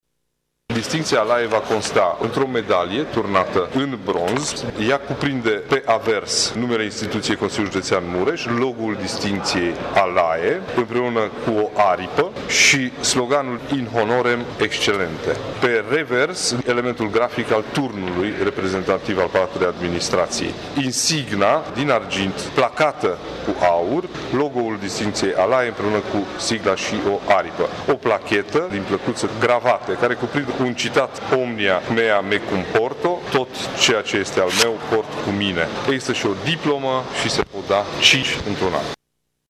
Președintele CJ Mureș, Ciprian Dobre: